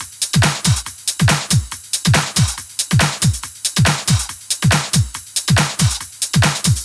VFH2 140BPM Lectrotrance Kit 2.wav